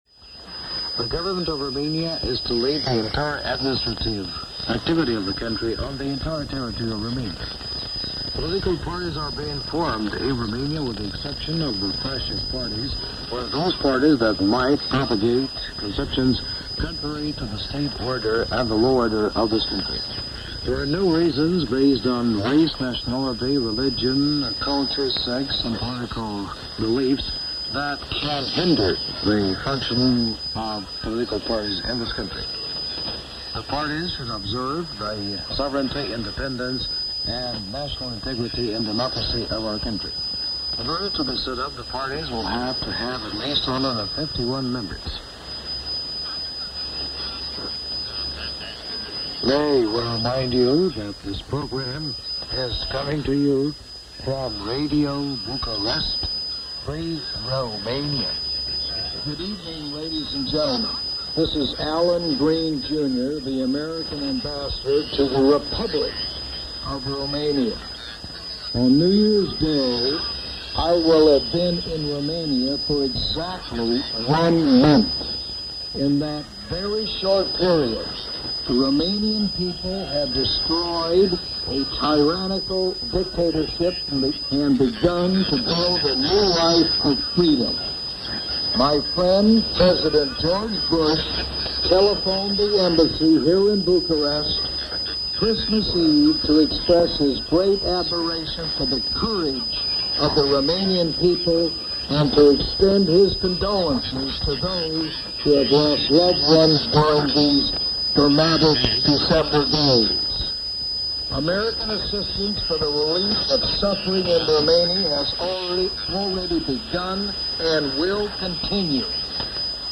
Radio Bucharest – December 24, 1989 – January 1, 1990 – Shortwave Radio Archive Collection –